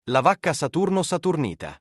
Звук лаваки сатурниты